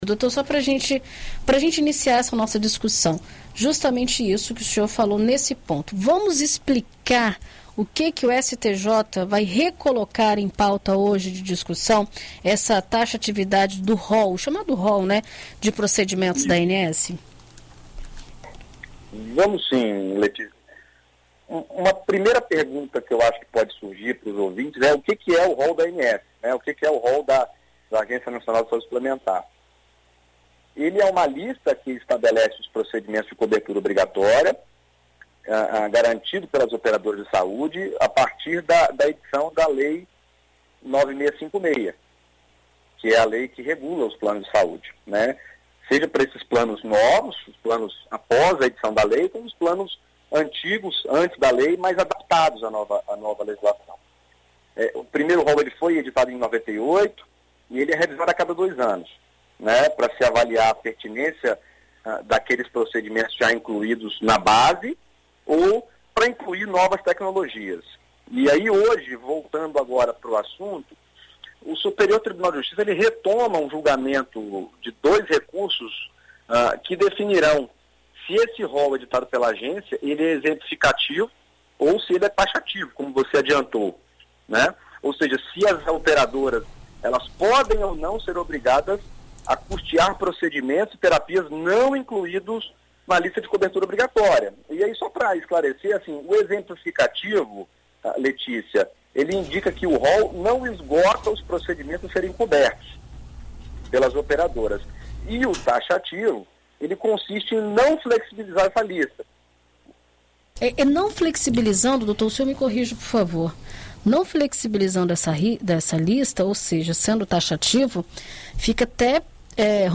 Em entrevista à BandNews FM Espírito Santo nesta quarta-feira (23)